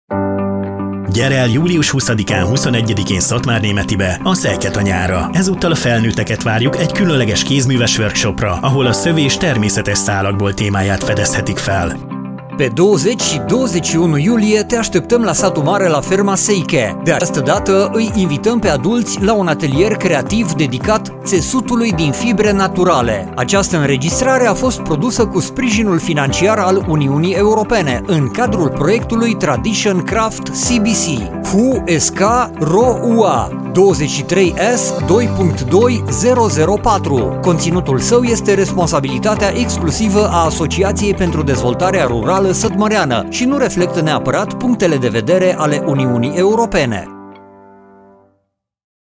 Radio spot - Atelier pentru adulti - Kézműves műhely felnőtteknek